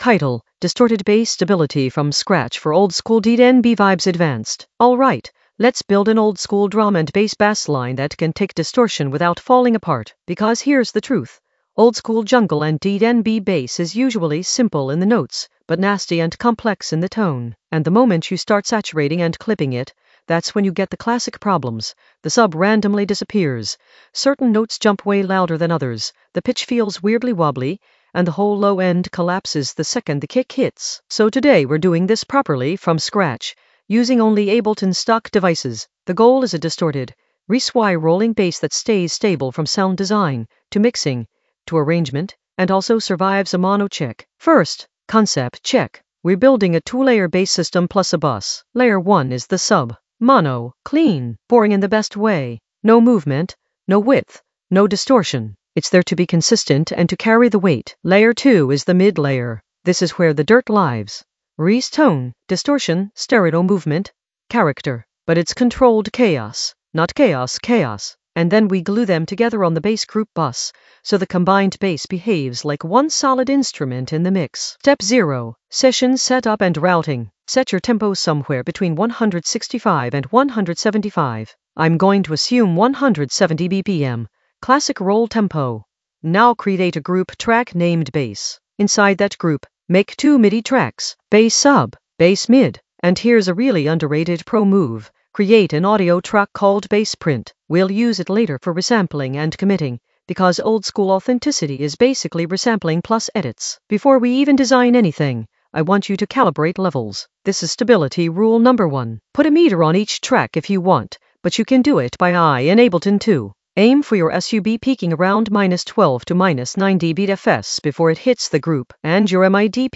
Narrated lesson audio
The voice track includes the tutorial plus extra teacher commentary.
An AI-generated advanced Ableton lesson focused on Distorted bass stability from scratch for oldskool DnB vibes in the Basslines area of drum and bass production.